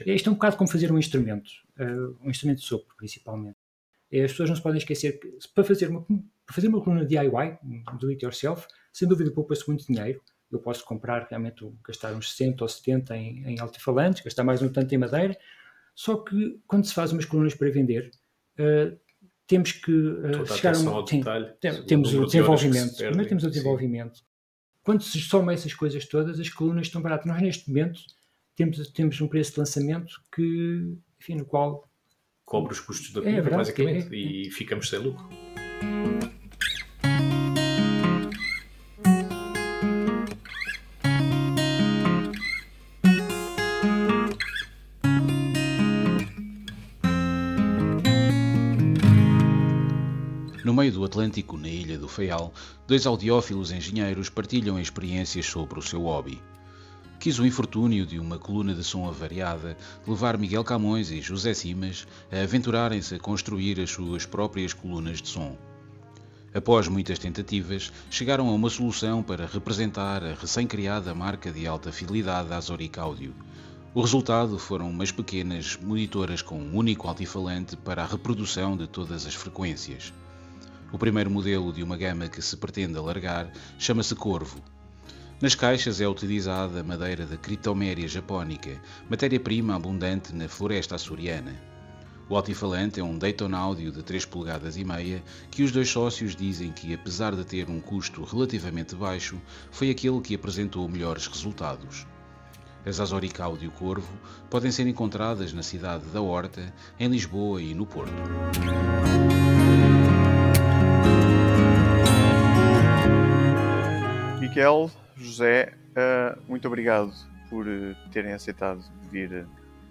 Música & Som - À conversa com a AzoricAudio